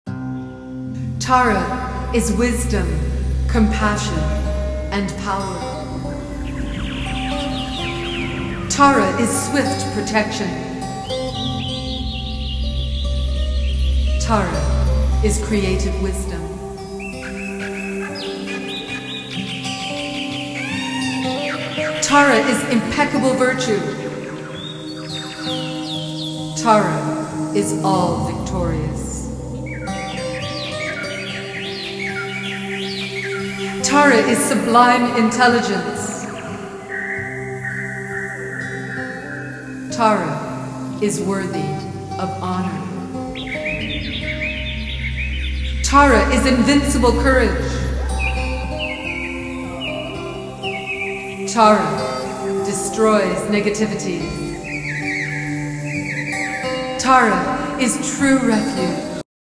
Qualities-Birdsong-Meditation-Sample.m4a